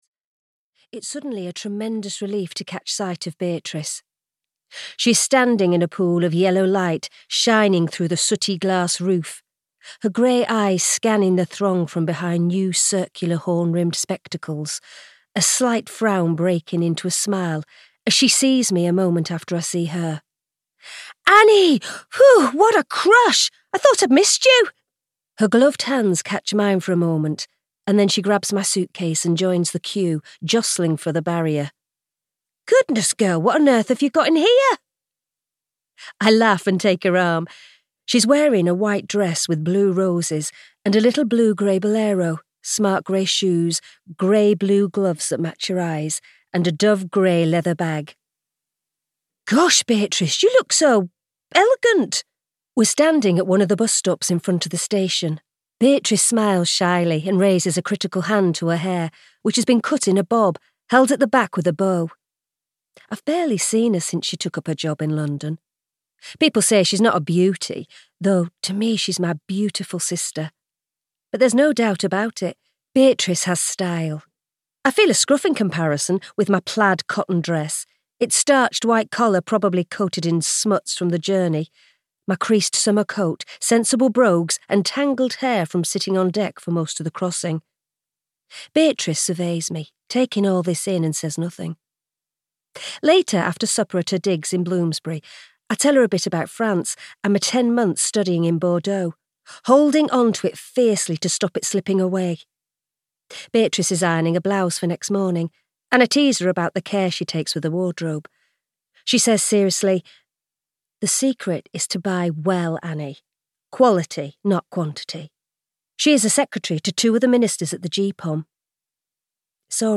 Audio knihaThe Dissent of Annie Lang (EN)
Ukázka z knihy